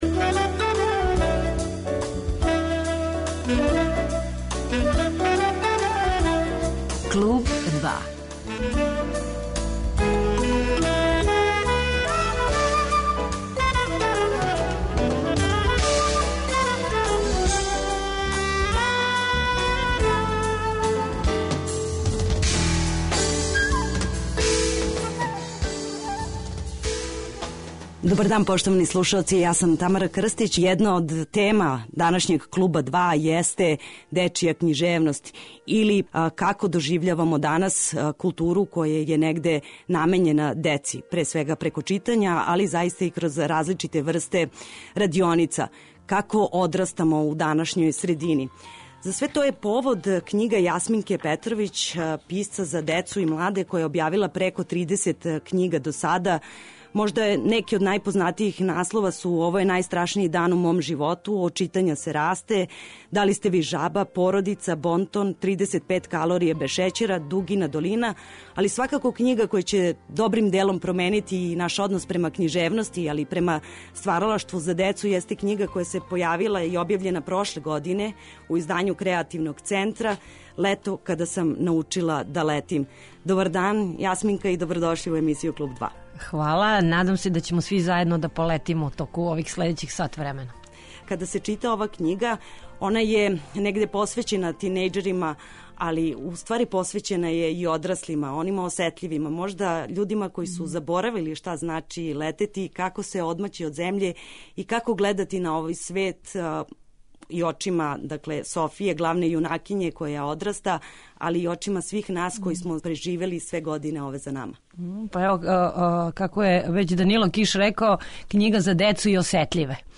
Слушаћете разговор